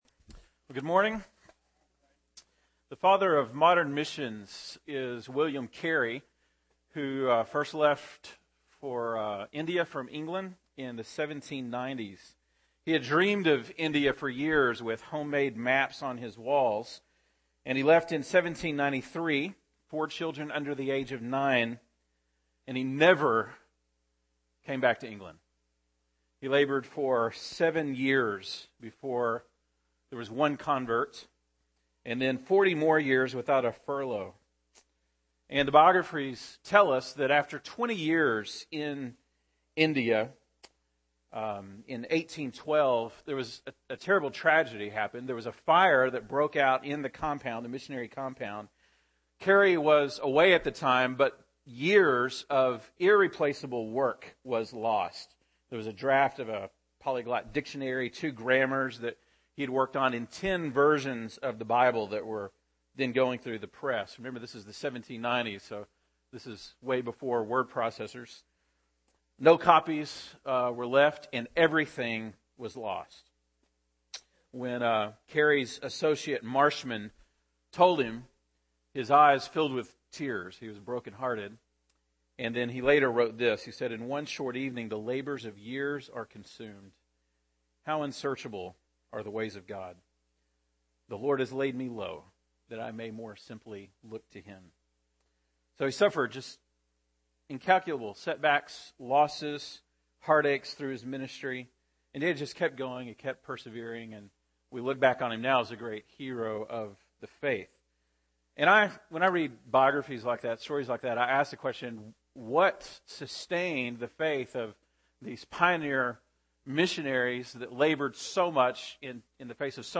January 20, 2013 (Sunday Morning)